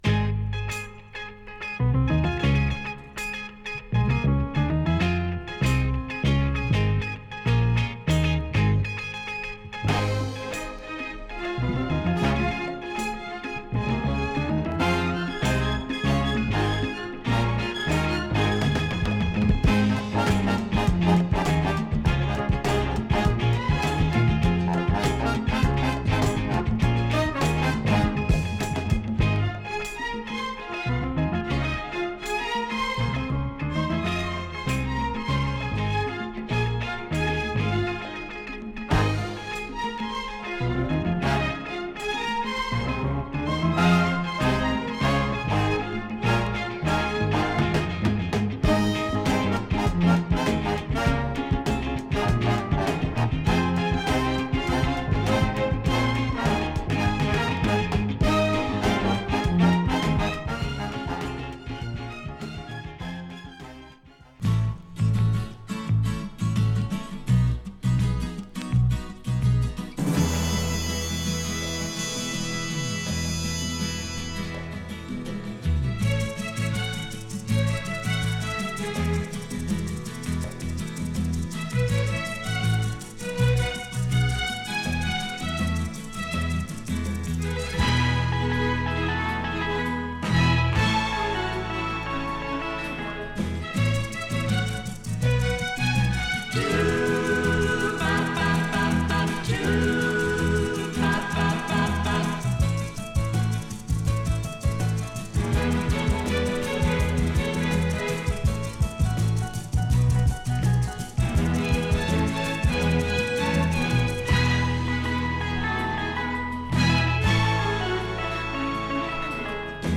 ストリングスを加えたビックバンドに効果的なスキャット＆コーラスが入る英国ラウンジ作品。